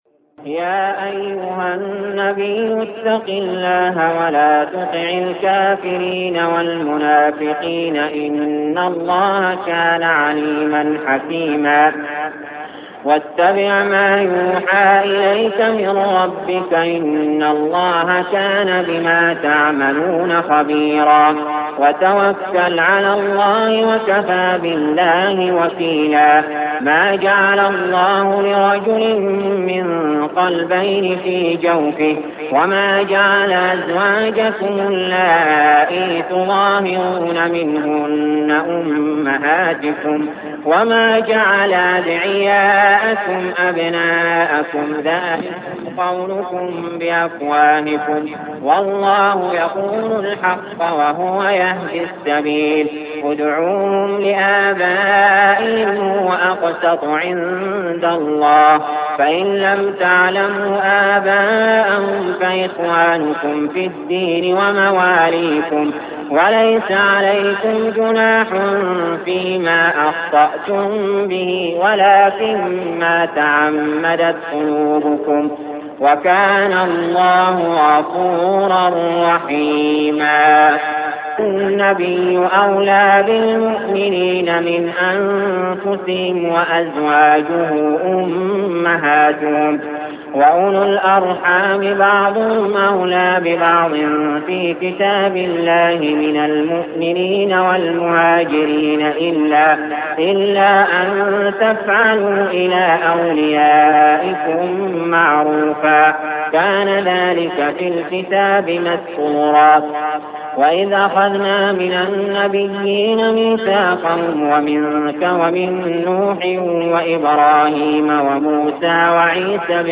المكان: المسجد الحرام الشيخ: علي جابر رحمه الله علي جابر رحمه الله الأحزاب